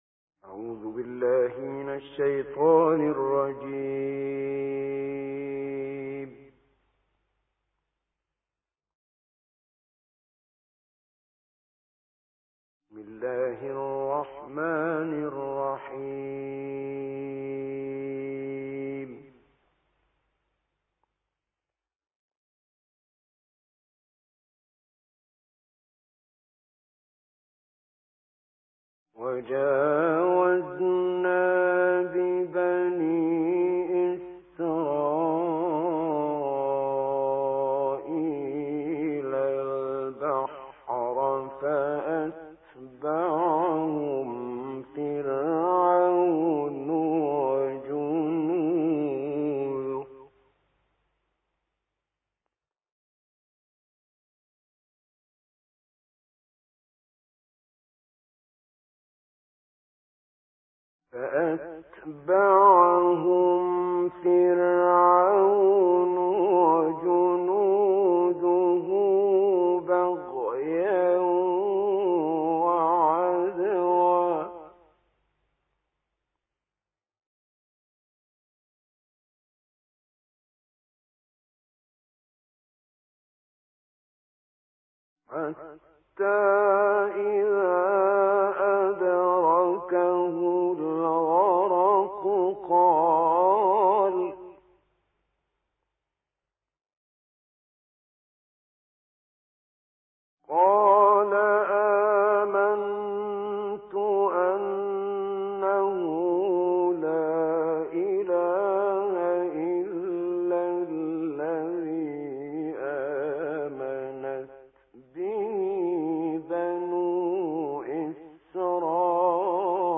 تلاوت استودیویی
تلاوت استودیویی آیاتی از سوره‌های یونس و هود